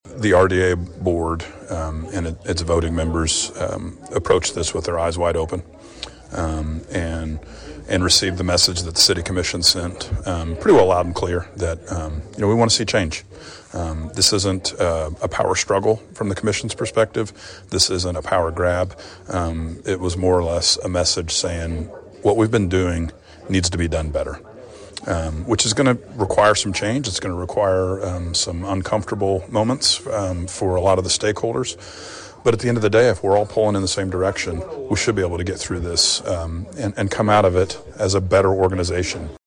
Emporia City Commissioner Jamie Sauder and RDA board member says Friday’s move by the RDA board reflected the City Commission’s desire for improvement — nothing more.